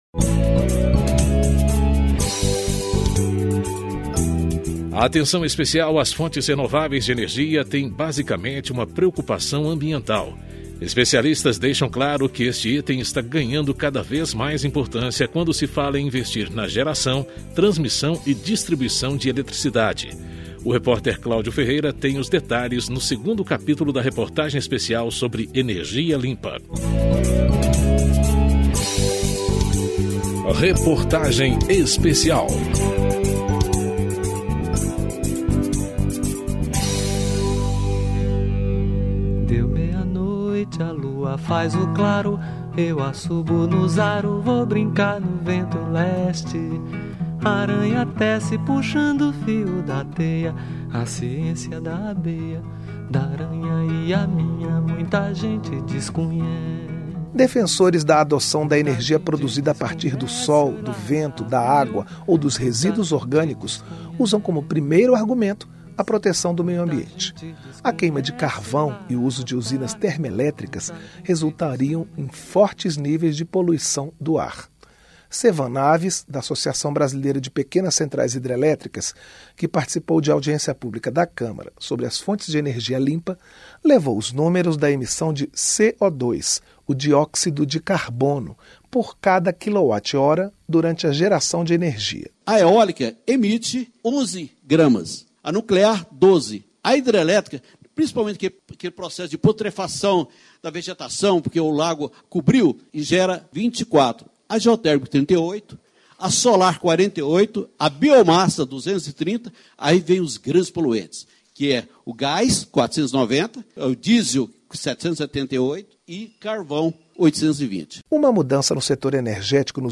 Reportagem Especial